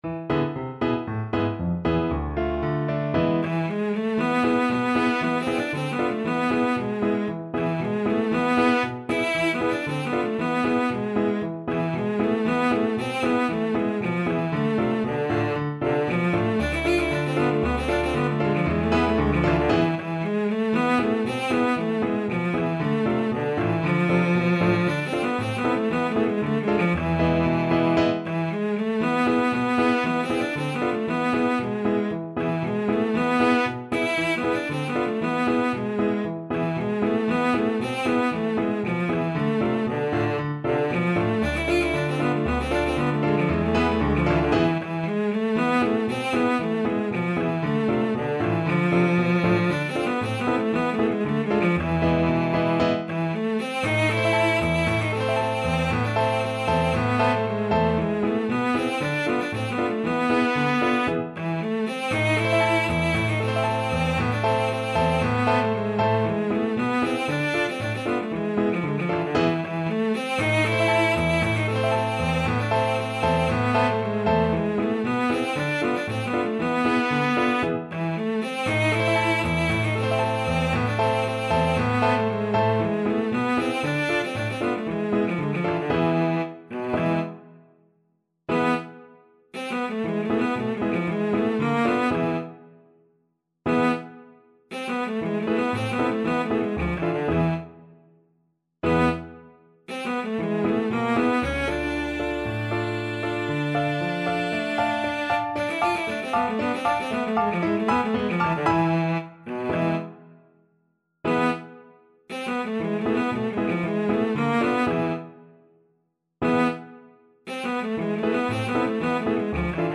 Traditional Trad. Der Heyser Bulgar (Klezmer) Cello version
Cello
Allegro =c.116 (View more music marked Allegro)
2/4 (View more 2/4 Music)
A minor (Sounding Pitch) (View more A minor Music for Cello )
Traditional (View more Traditional Cello Music)